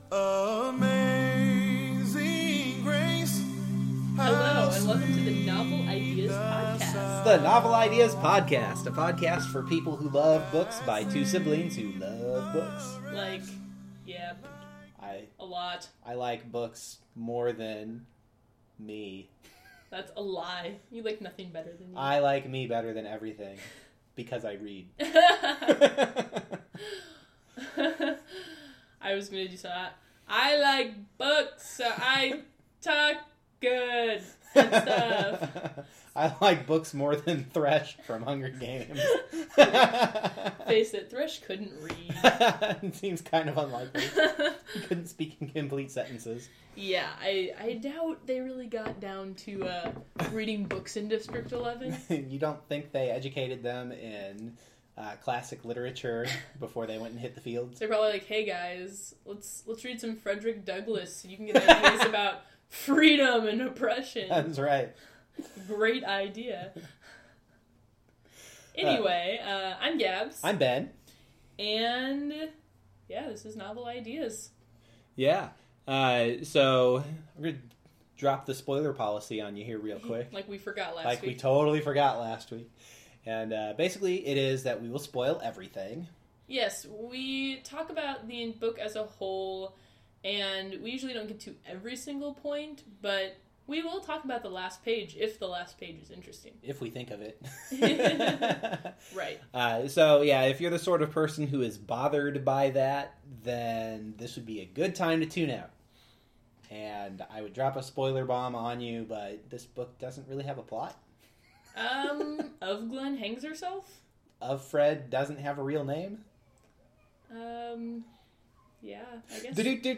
Welcome back, book lovers! This week’s episode features A Handmaid’s Tale by Margaret Atwood, a dystopian future where women are second class citizens. The conversation is a little longer and darker than average as we take turns atop the feminist soapbox. We also attempt to offend the whole world by discussing religion and motherhood. Our high standards of broadcast quality are maintained as a landscaper starts his leafblower and leaves it sitting outside the window.